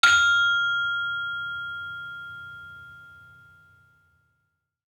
Saron-4-F5-f.wav